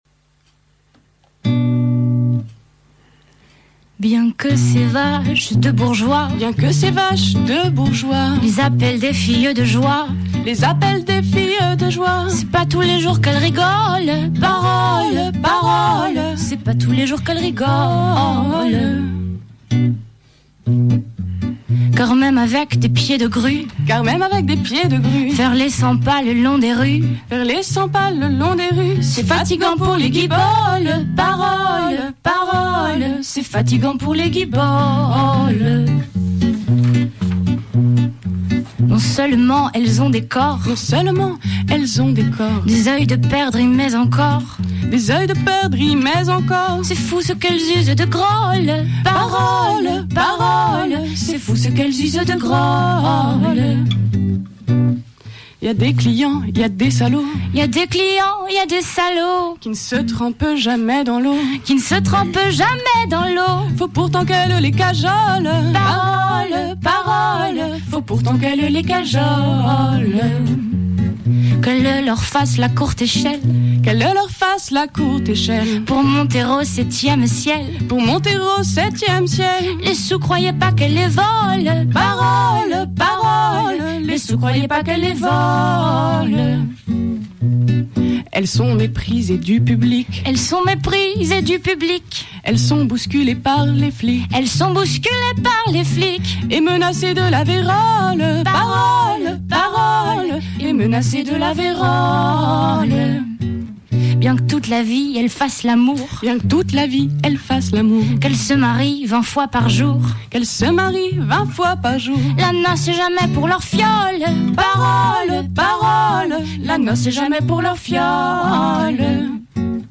A l’occasion du Printemps des Poètes 2013, cette année consacré à la thématique « Les voix du poème », le théâtre de la Gamelle fait entendre une sélection de poèmes d’hier et d’aujourd’hui, coup de cœurs des lecteurs présents… chantés, lus, partagés.